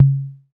TR-55 LOW 0O.wav